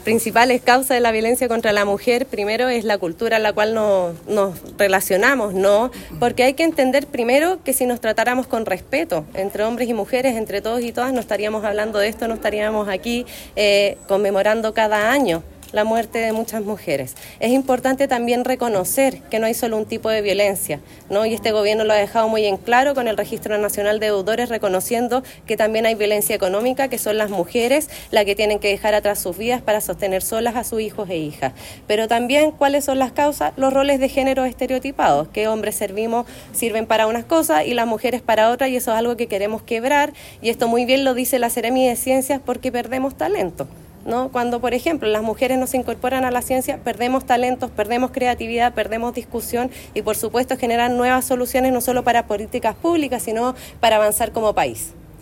La actividad se realizó en la Sala de Sesiones del Municipio y contó con la participación de autoridades regionales, provinciales y locales; además de dirigentas sociales.
Del mismo modo, Macarena Gré indicó que los distintos tipos de violencia que sufren las mujeres son múltiples que abarcan los aspectos físicos, psicológicos y económicos, por lo que se han trabajado distintas políticas públicas que respondan a esta necesidad.